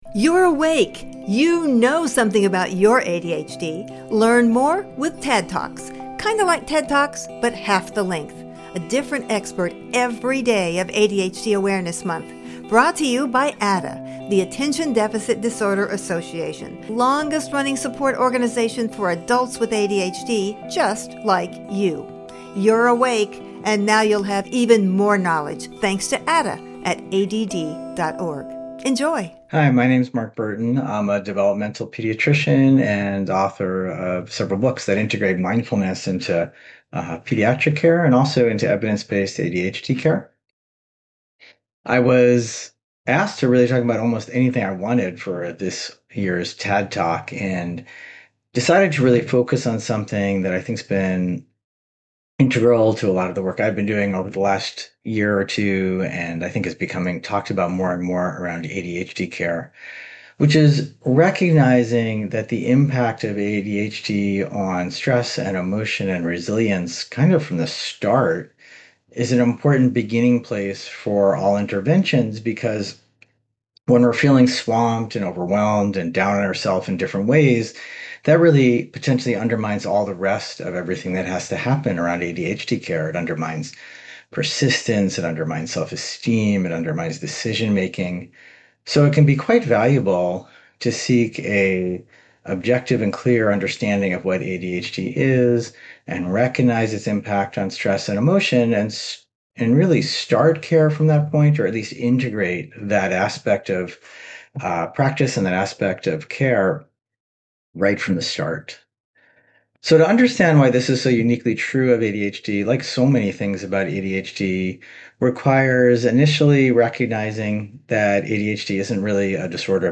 Facebook Instagram LinkedIn TADD TALK DOWNLOAD THE TRANSCRIPT SEE ALL 2025 TADD TALKS